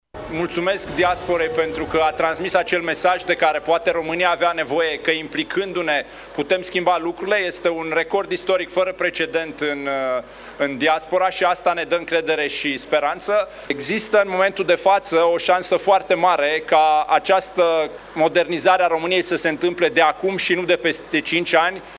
Am văzut rezultatele exit-poll-urilor, sunt rezultate care ne încurajează, rămânem pozitivi şi încrezători’, a afirmat Dan Barna, la sediul USR, după anunţarea exit-poll-urilor, care îl situau pe poziţia a treia.